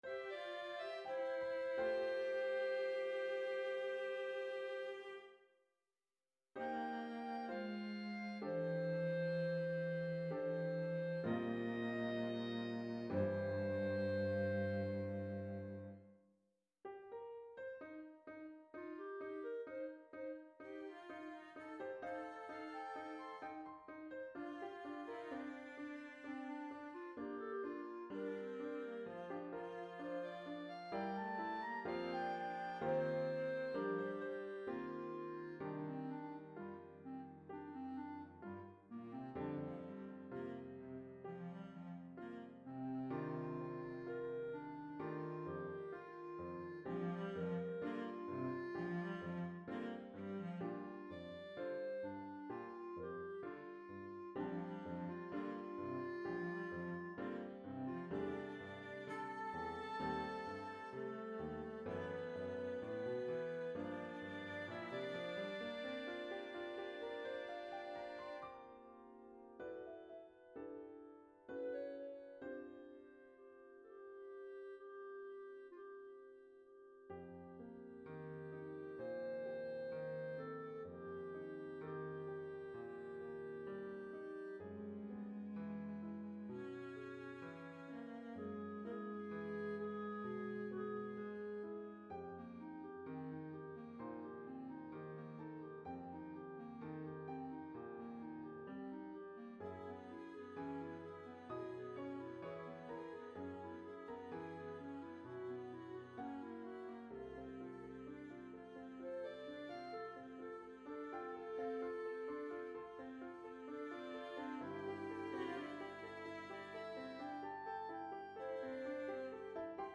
for piano trio: cl, vc, and pno. Listen to the midi sample